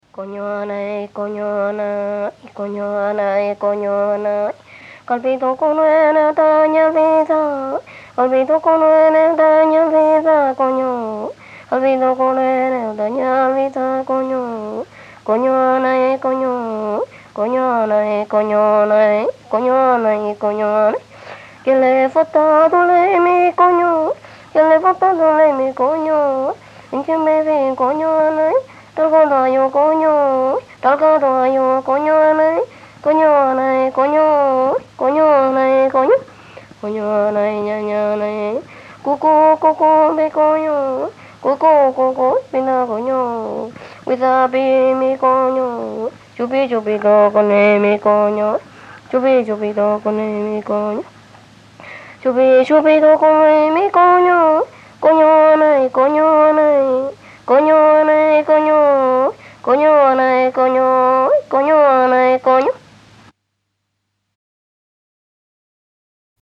Música mapuche
Música tradicional
Folklore
Música vocal